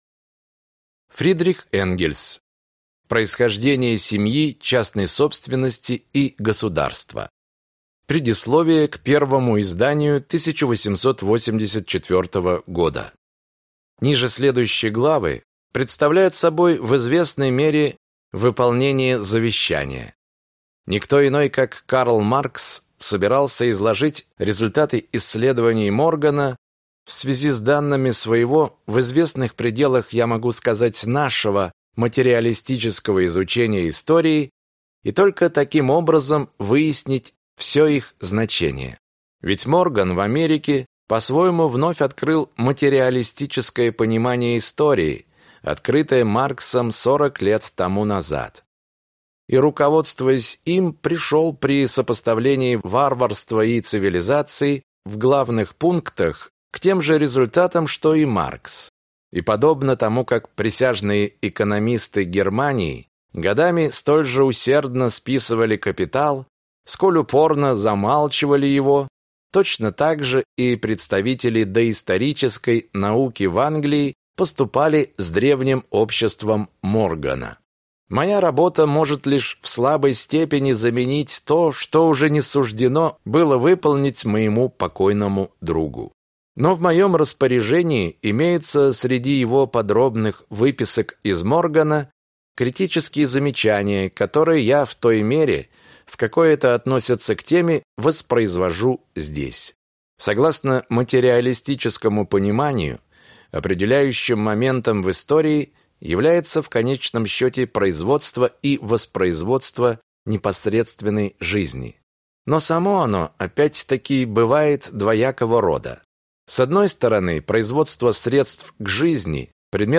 Аудиокнига Происхождение семьи, частной собственности и государства | Библиотека аудиокниг